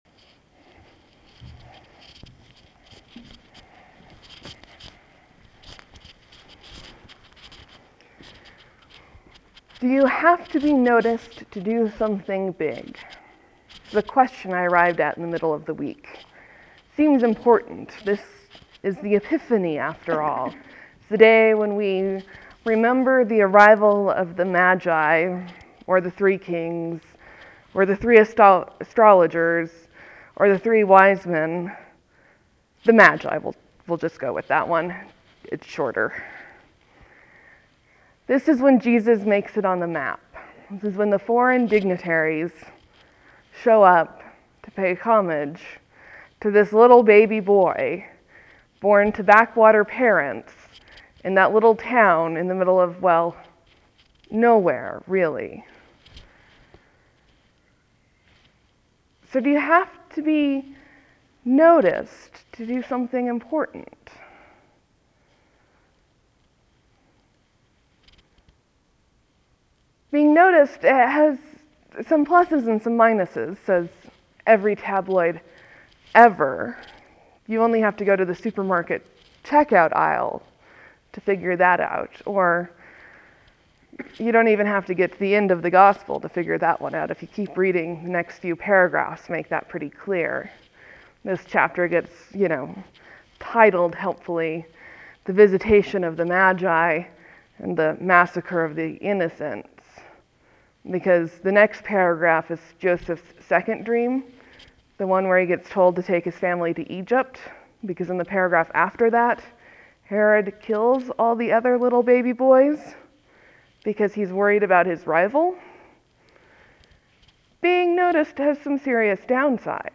(There will be a few moments of silence before the sermon begins. Thank you for your patience.)